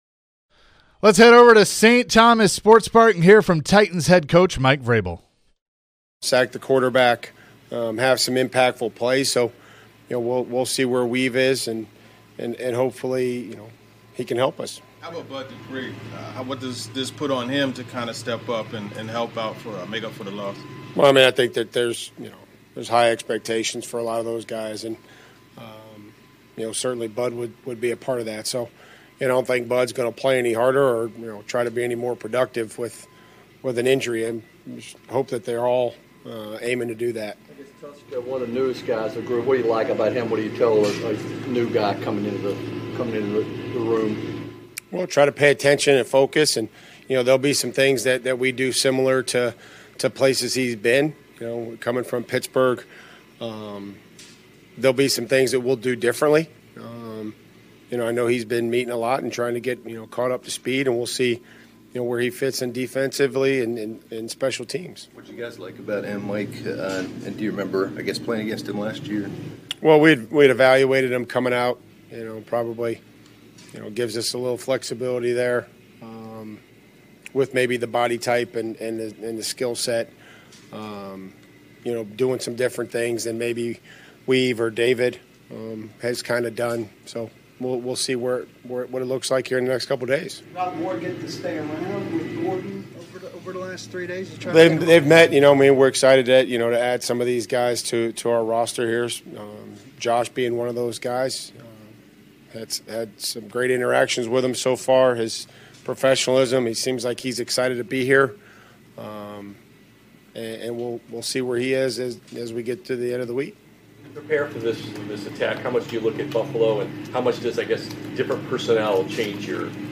Mike Vrabel presser 9-5-22